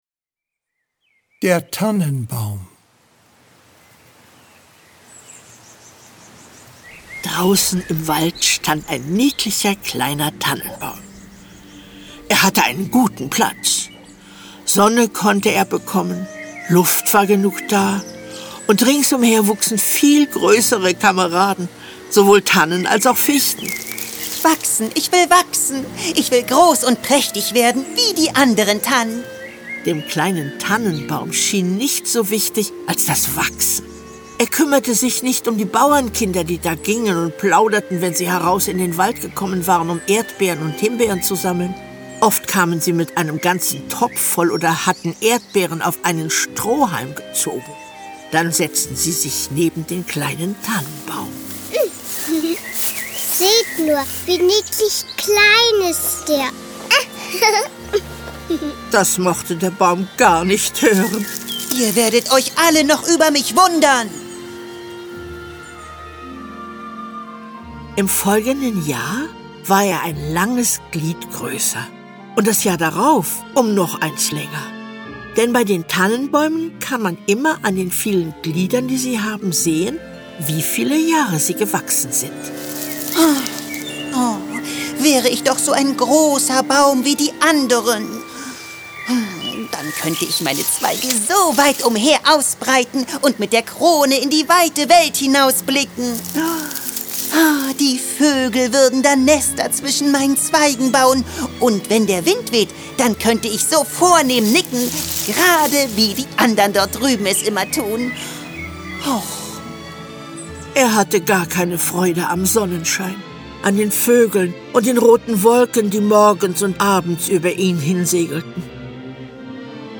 Titania Special 16. Hörspiel.